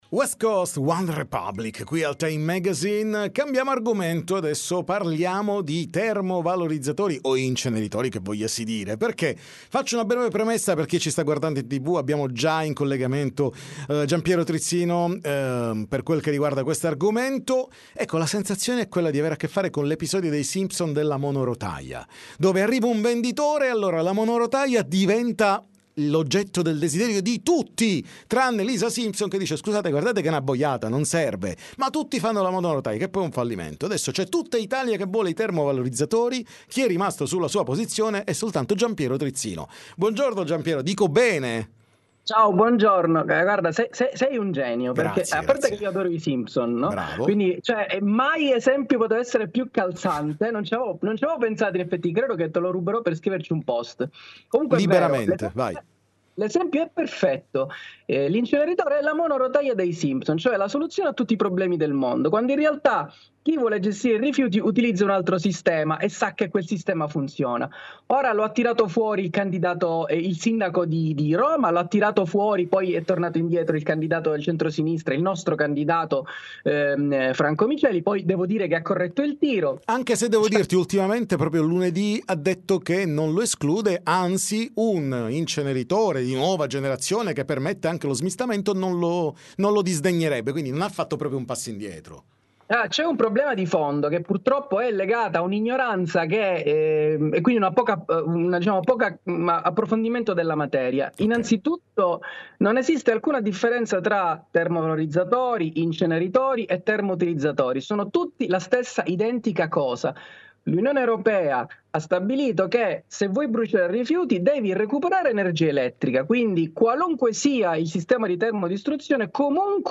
TM Intervista Giampiero Trizzino